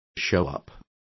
Also find out how aparecer is pronounced correctly.